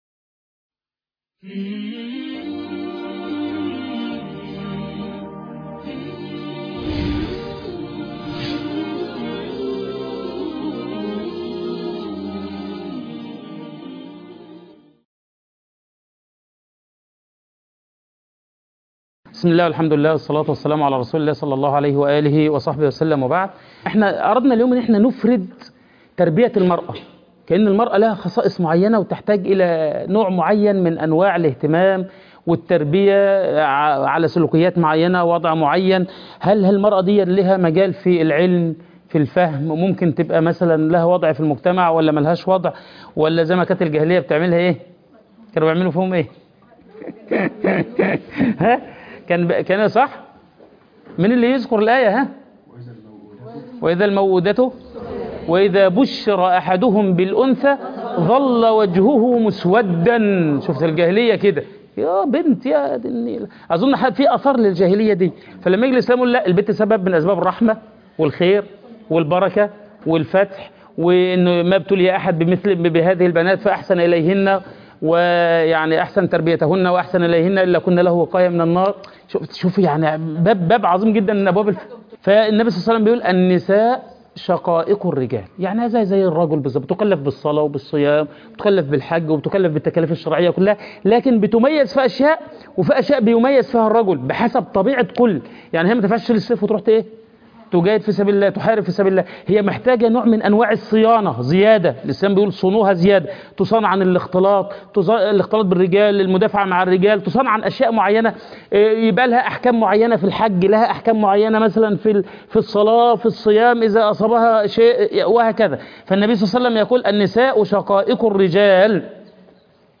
المحاضرة الحادية عشر أصول التربية الإسلامية